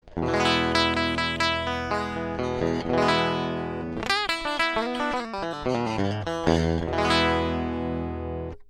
가는 현은 레인지가 넓게 엣지가 나오고, 굵은 현은 어택감이나 파워감이 있게 느껴집니다.